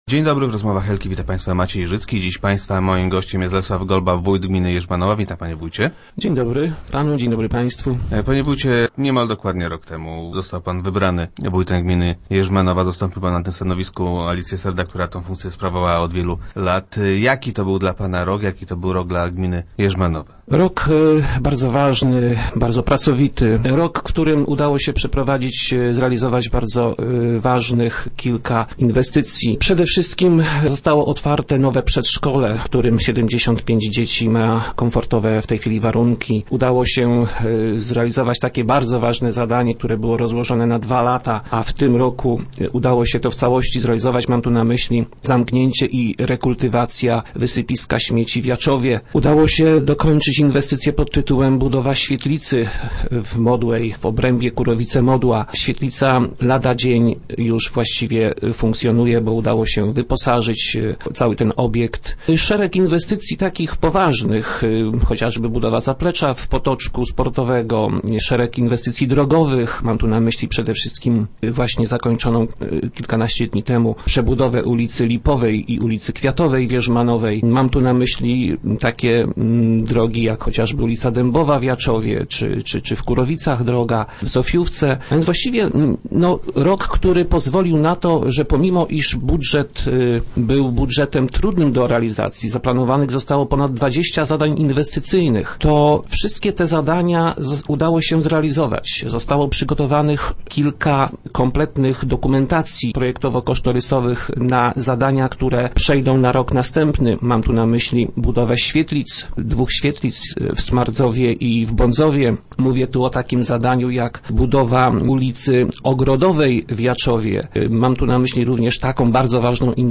Zdaniem Lesława Golby, który był gościem piątkowych Rozmów Elki, był on całkiem udany.